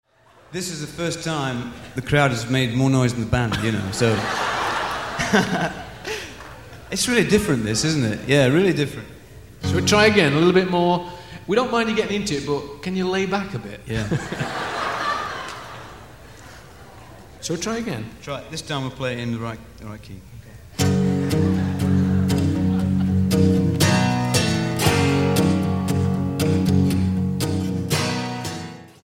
STYLE: Jesus Music
three bonus live tracks recorded in California